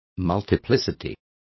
Complete with pronunciation of the translation of multiplicity.